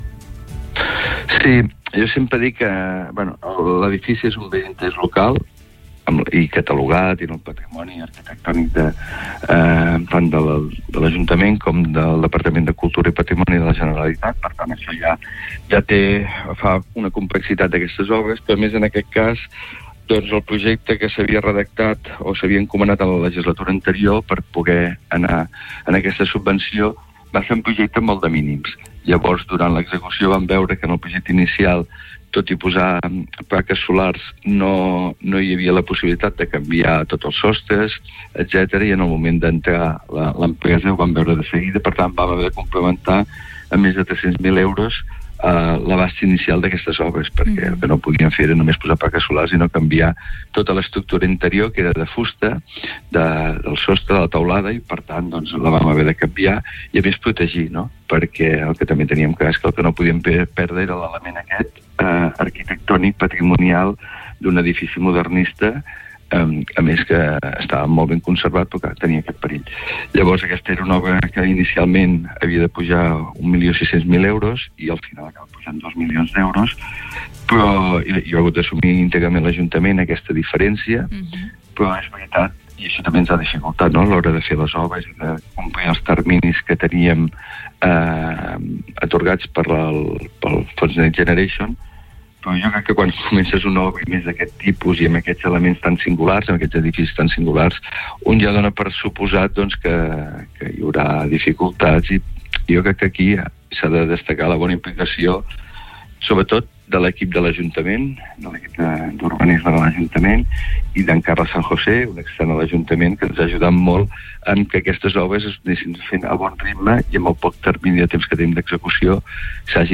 Entrevistes SupermatíNotícies